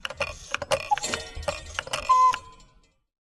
Настенные часы с кукушкой - Вариант 2 (сломались)
• Категория: Настенные и напольные часы с кукушкой
• Качество: Высокое